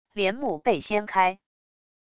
帘幕被掀开wav音频